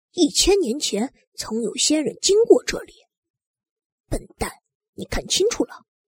女声
正太-小仙童